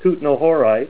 Say KUTNOHORITE Help on Synonym: Synonym: ICSD 202247   Kutnahorite   PDF 11-345